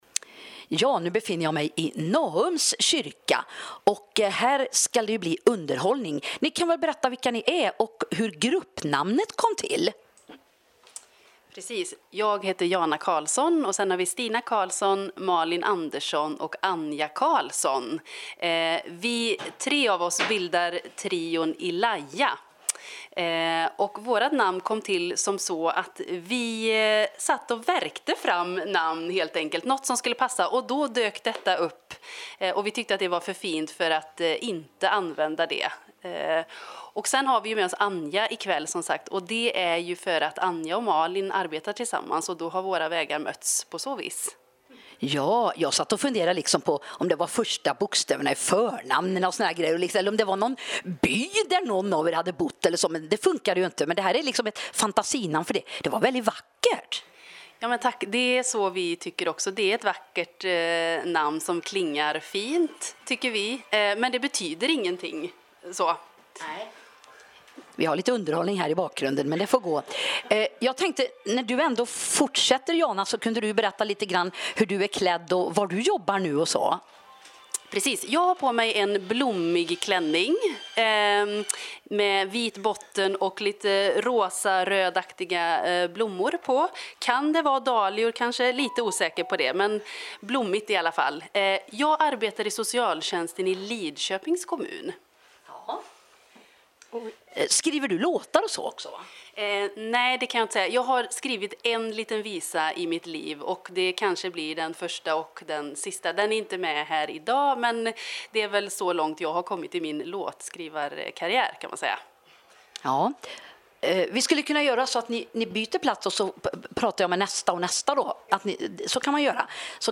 Ilaya-sanggrupp-intervju.mp3